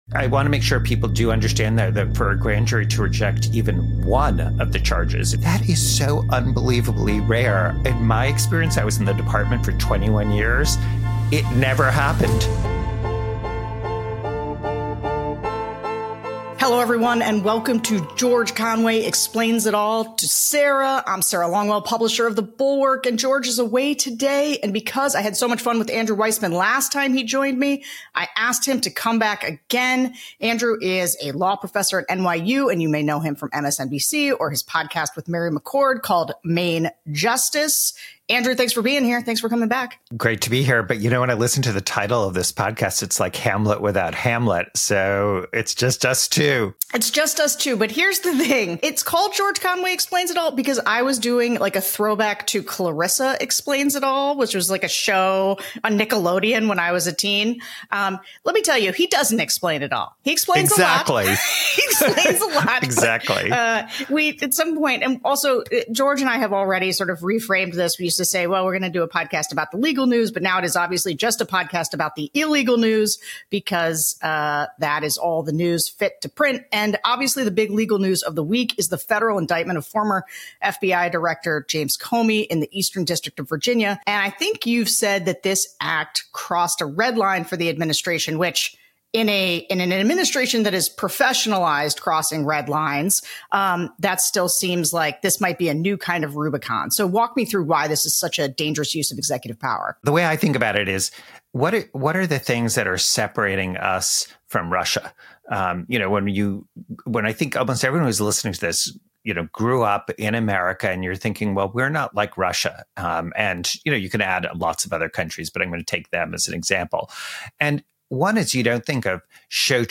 Donald Trump’s DOJ just indicted James Comey—but is the case already falling apart? Sarah Longwell and Andrew Weissmann (filling in for George Conway) get into why this prosecution is weak, dangerous, and could blow up in Trump’s face.